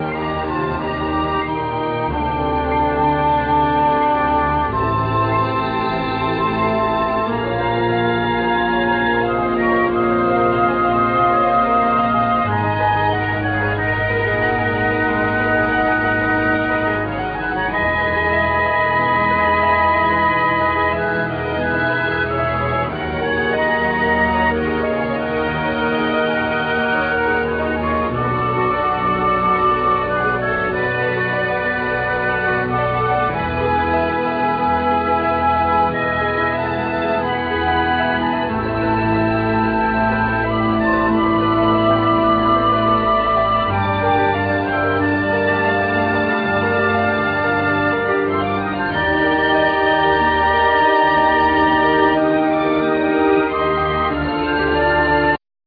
Harp
Bamboo Flute